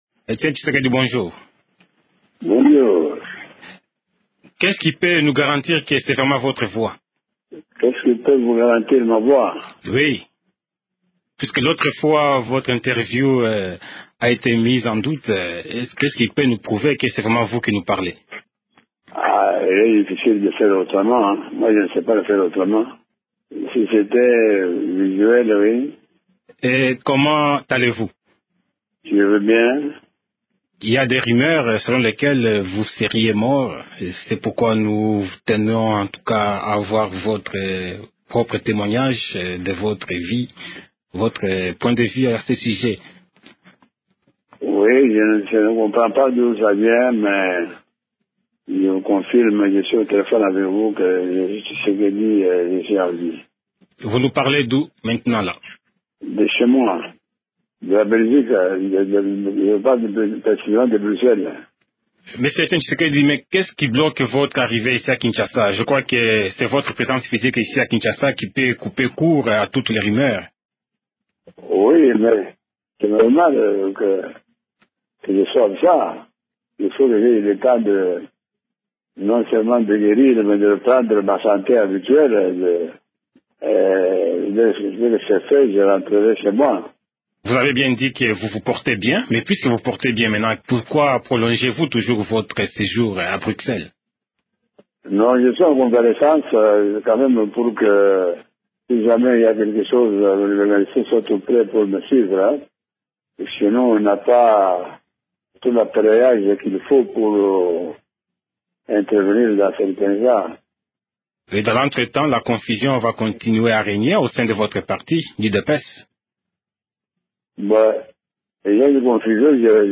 Alors qu’une rumeur circulait avec insistance en fin de semaine, à Kinshasa, selon laquelle le président national de l’UDPS (Union pour la démocratie et le progrès social) serait mort, le concerné est sorti de son silence pour porter un démenti formel. Etienne Tshisekedi s’entretient avec